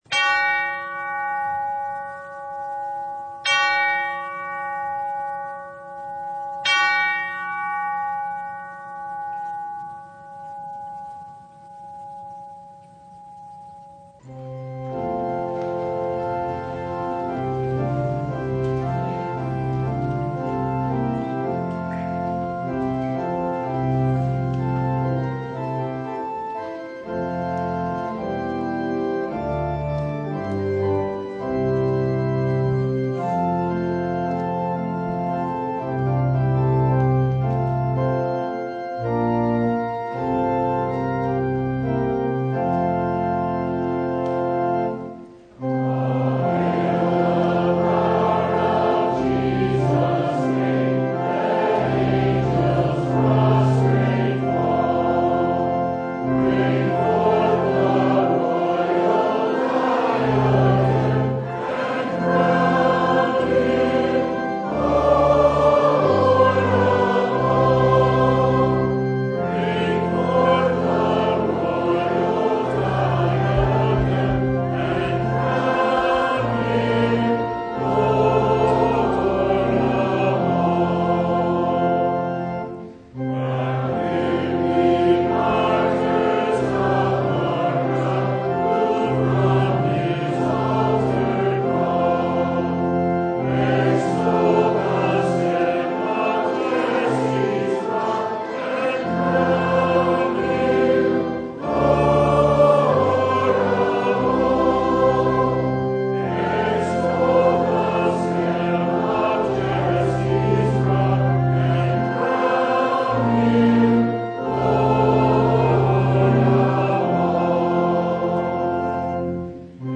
Luke 24:44-53 Service Type: The Feast of the Ascension of Our Lord We look to the sky and pray